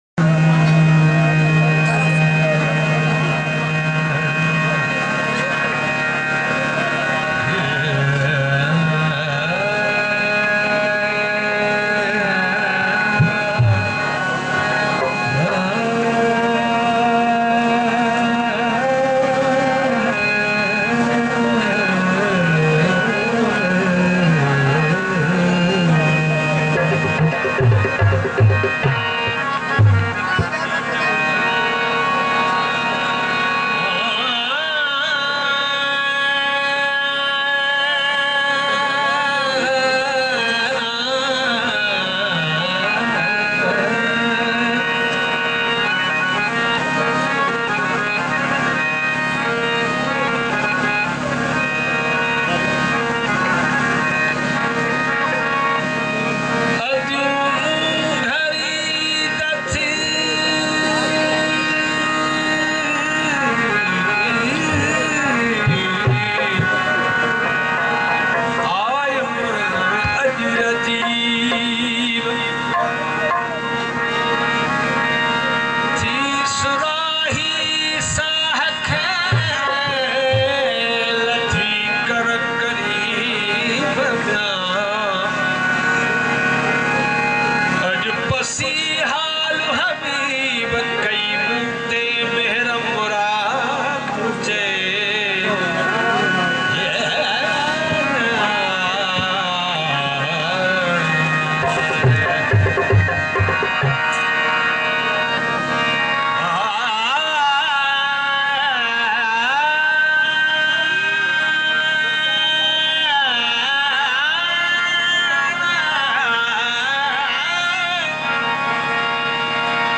Sindhi Sufi Kalam and Songs ﺴﻨﺪﻫﻰ